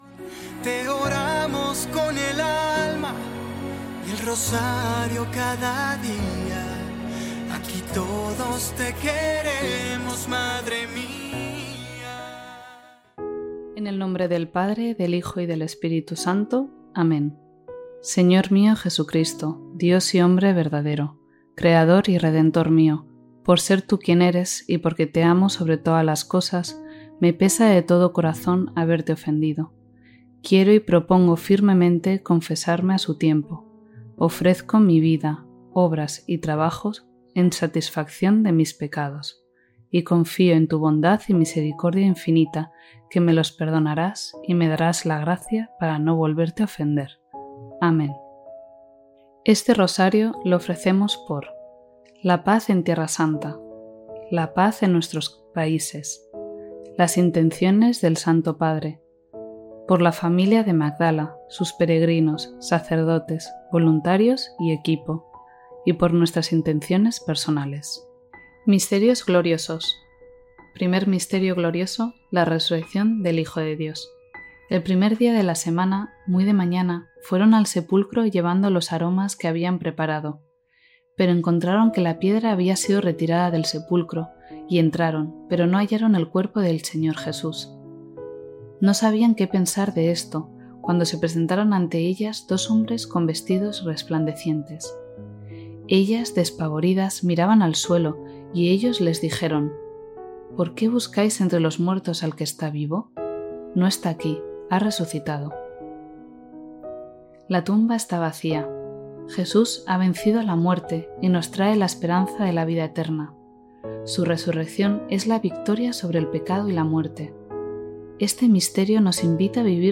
Rosario desde Tierra Santa | Magdala